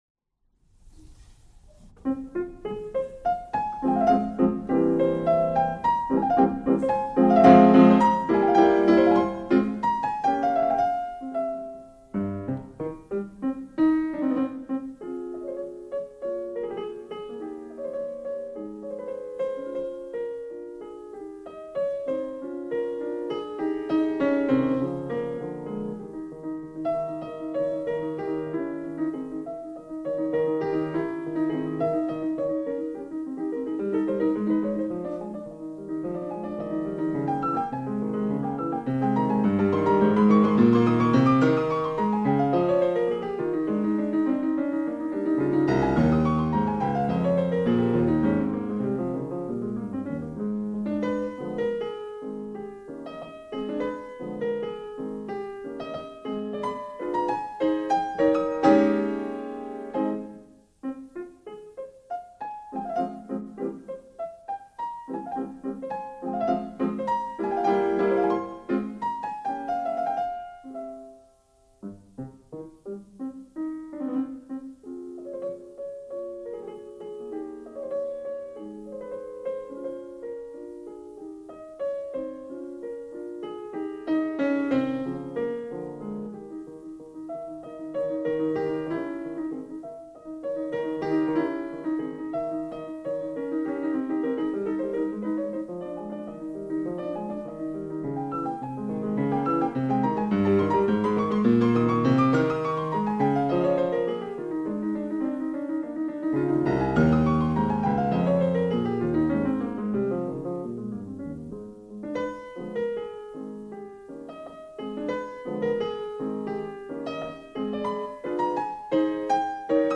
录音模式：MONO
此次大师是按照顺序演奏了32首钢琴奏鸣曲，演出地点均为文京公会堂